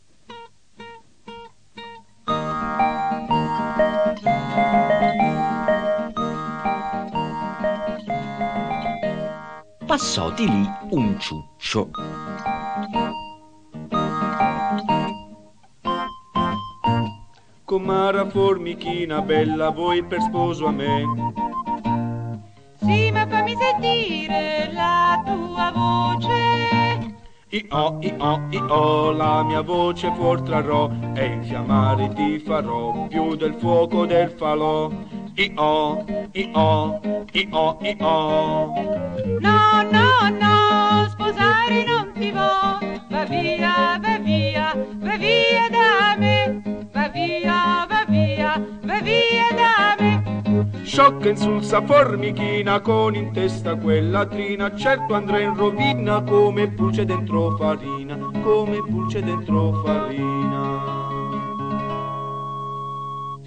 Musiche di Giovanni Iaffaldano
CIUCCIO             (Entra da sinistra, vede la formichina e, canta La sfilata degli animali )